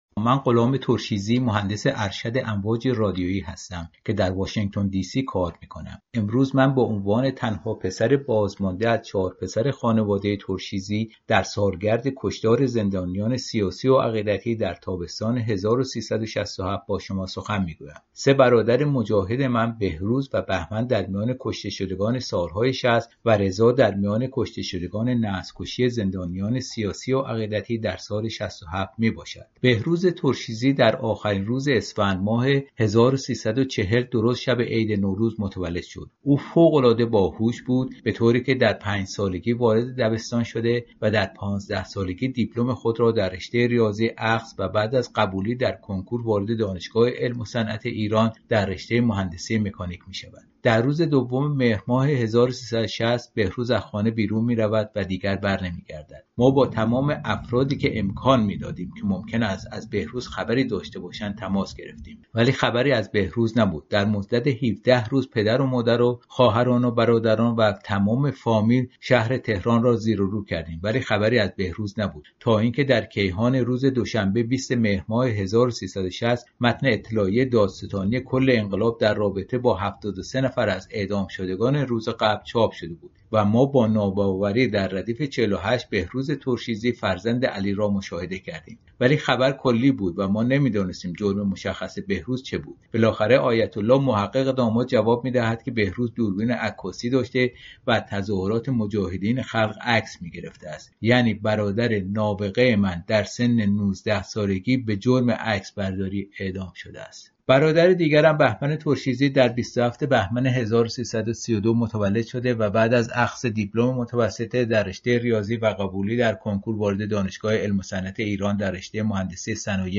در سی‌ودومین سالگرد اعدام‌های سیاسی سال ۶۷، روز جمعه ۱۴ شهریور مراسمی با عنوان «قتل‌عام ۶۷» در مقابل ساختمان کنگره آمریکا برگزار شد.